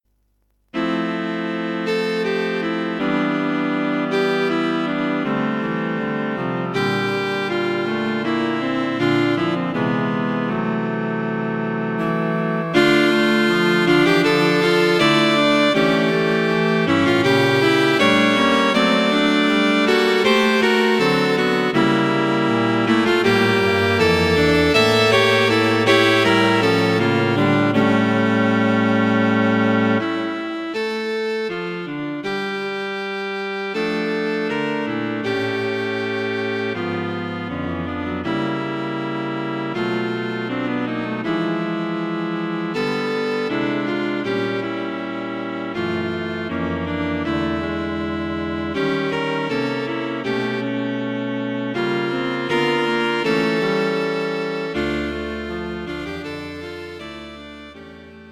Cello Quartet for Concert performance